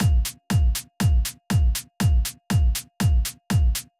Drumloop 120bpm 02-C.wav